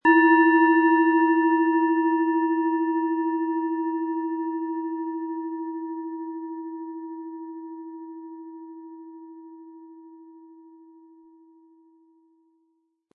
Hopi Herzton
Es ist eine von Hand gearbeitete tibetanische Planetenschale Hopi-Herzton.
Unter dem Artikel-Bild finden Sie den Original-Klang dieser Schale im Audio-Player - Jetzt reinhören.
SchalenformBihar
MaterialBronze